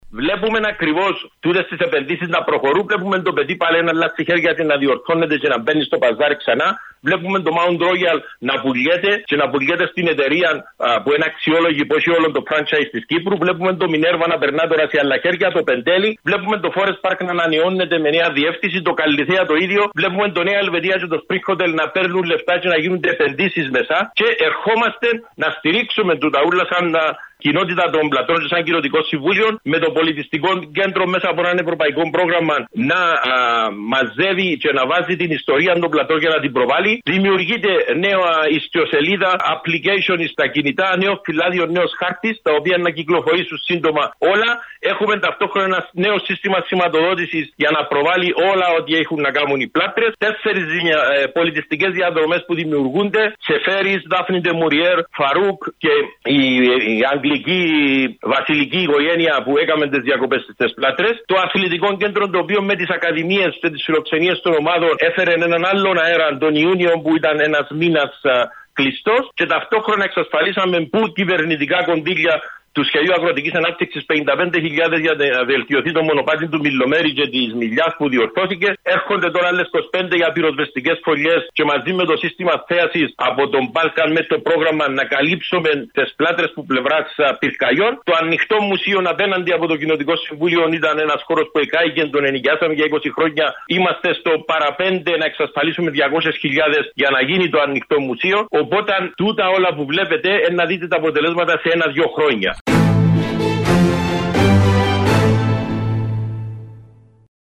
Μέσα στα επόμενα δύο χρόνια οι Πλάτρες αναμένεται να αλλάξουν εικόνα αφού έχουν γίνει σημαντικές επενδύσεις σε ξενοδοχειακές εγκαταστάσεις που αναβαθμίζονται και θα λειτουργήσουν προσεχώς, όπως τόνισε μιλώντας στο ΚΑΝΑΛΙ 6 ο πρόεδρος του Κοινοτικού Συμβουλίου, Παναγιώτης Παπαδόπουλος.